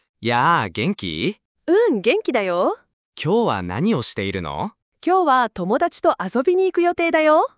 Google Gemini APIで複数話者テキスト音声合成（TTS）を実現！会話を自然な声で生成
• Puck -- Upbeat
• Kore -- Firm
このように、JoeとJaneのセリフがそれぞれ異なる声色で合成されていることがわかります。